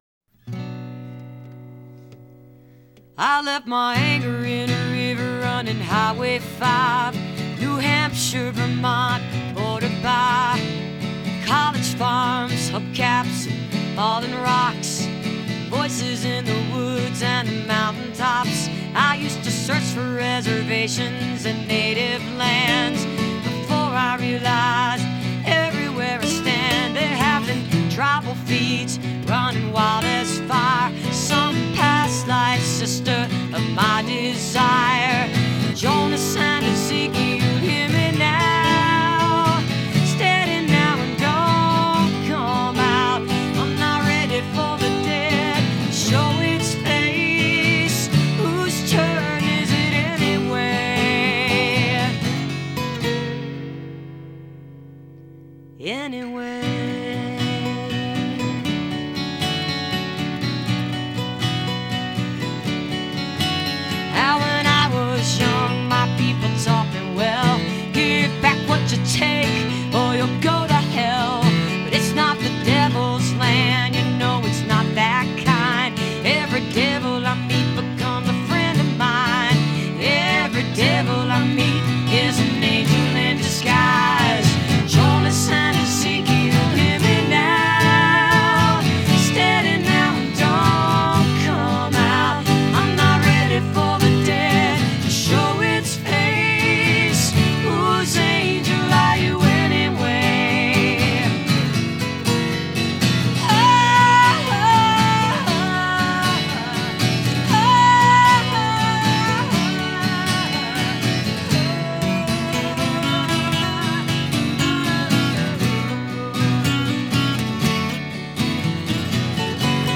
in studio performance